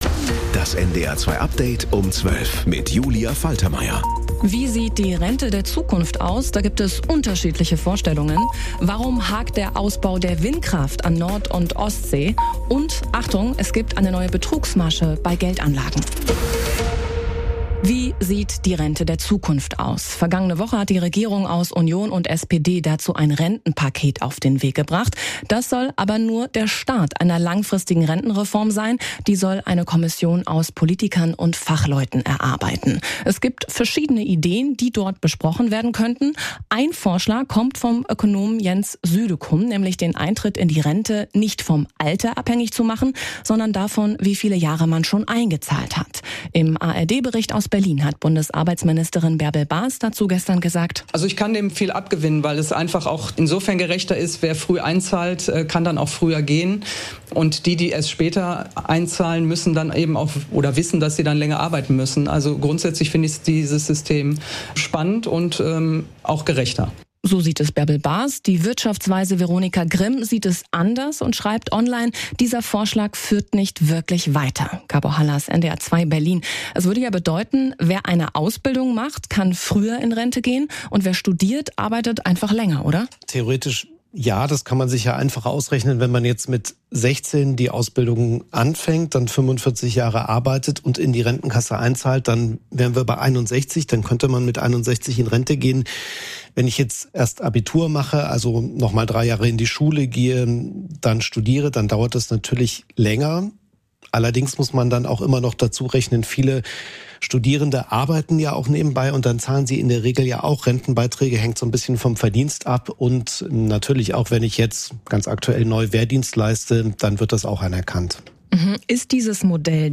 Mit unseren Korrespondent*innen und Reporter*innen, im Norden, in Deutschland und in der Welt.